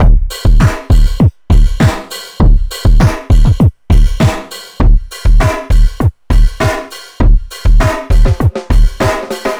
funkis 100bpm 03.wav